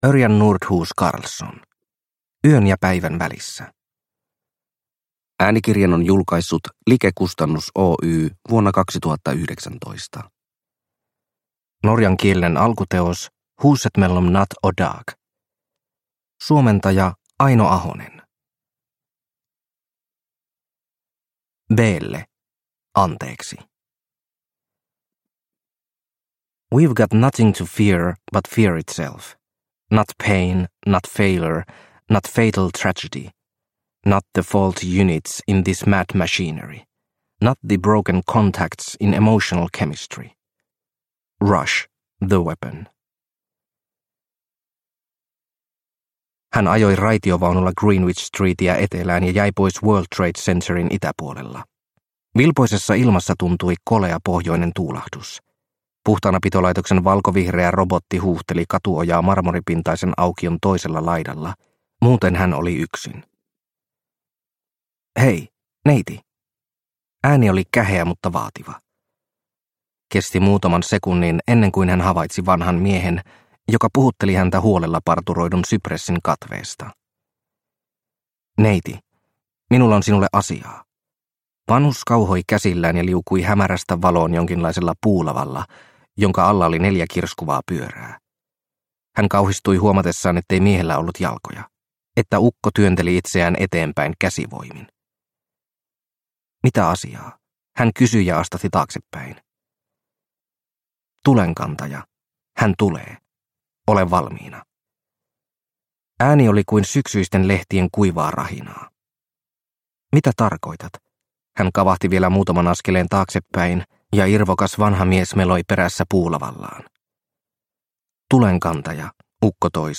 Yön ja päivän välissä – Ljudbok